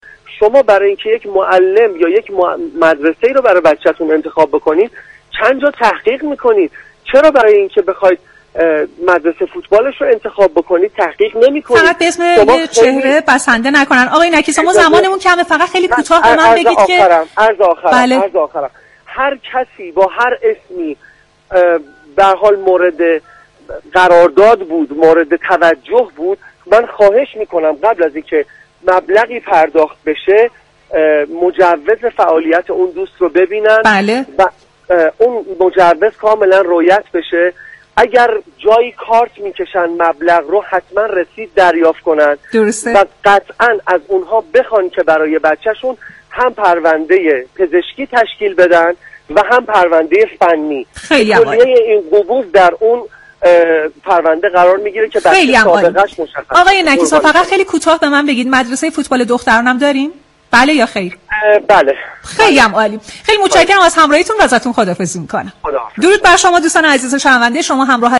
گزارشگر